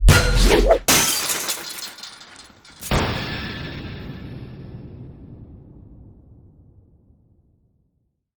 rooftopDoorSmash.ogg